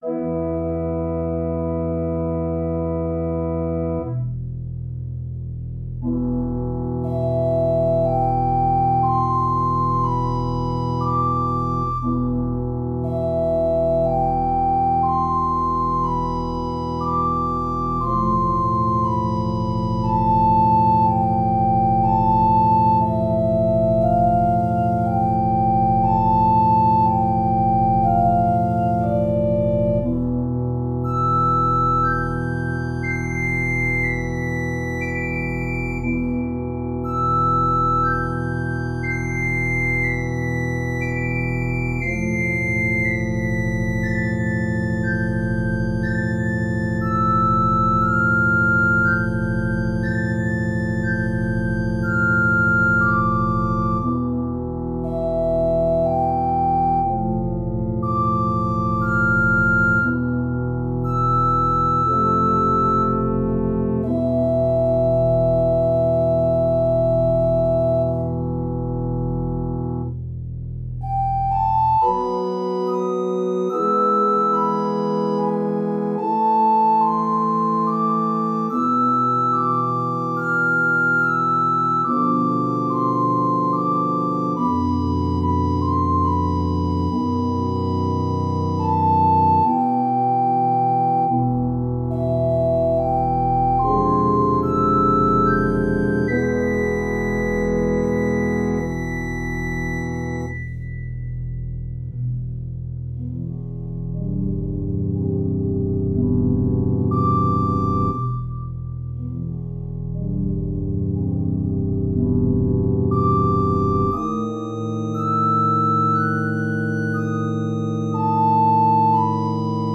Organ Prelude no. 2
I began writing this one (no. 2) in a minimalist style circa 2011.